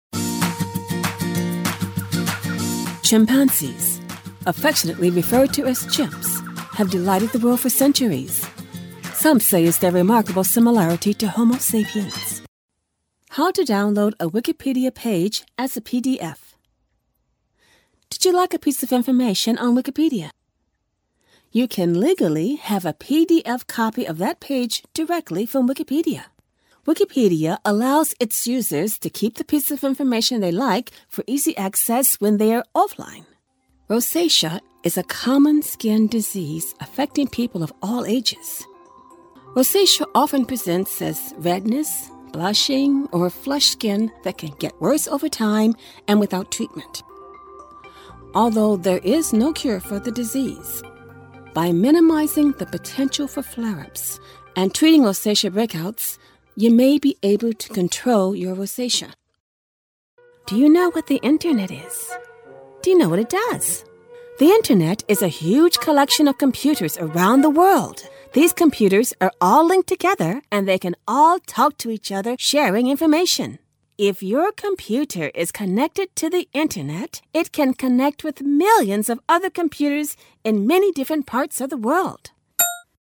Female
Adult (30-50), Older Sound (50+)
Corporate-Explainer-Elearning